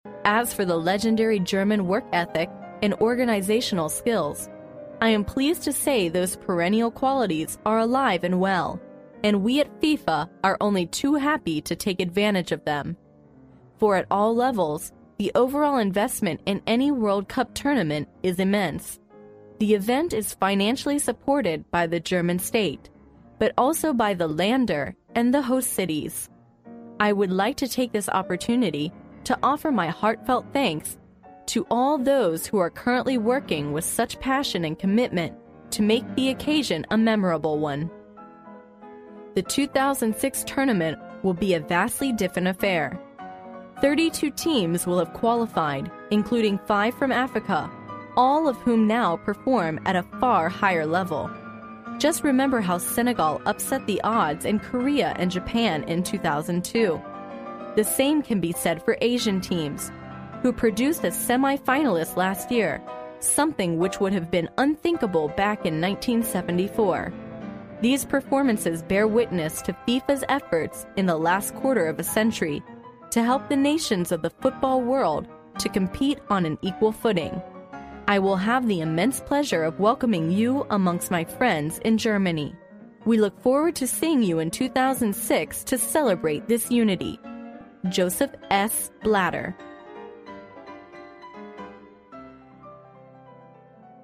历史英雄名人演讲 第90期:2006年世界杯开幕国际足联主席约瑟夫·布拉特致辞(2) 听力文件下载—在线英语听力室